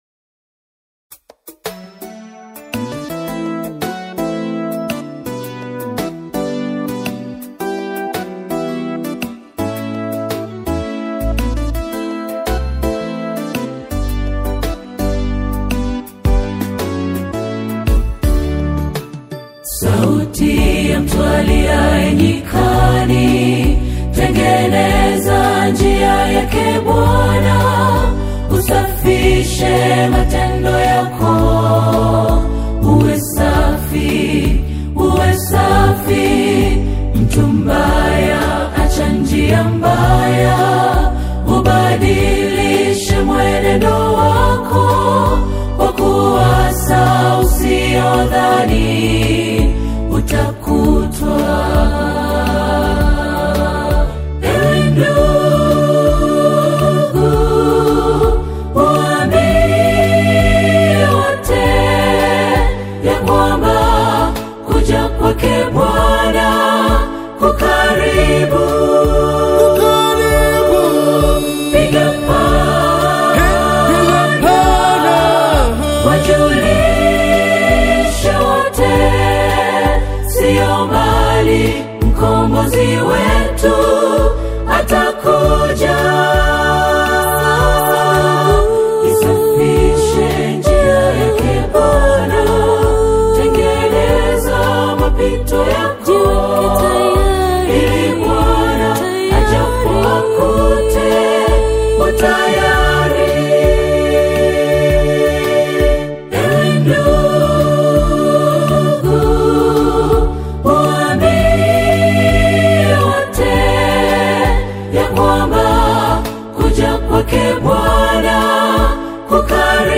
a sobering and prophetic new single